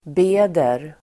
Uttal: [b'e:der]